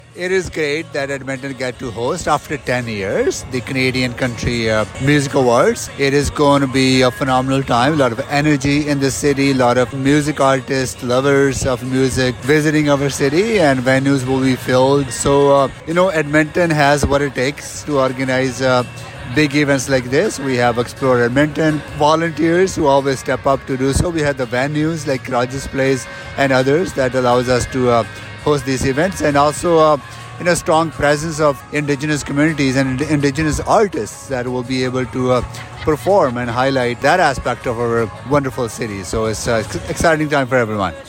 City of Edmonton Mayor Amarjeet Sohi spoke with CFWE about his excitement on the City hosting the CCMA’s.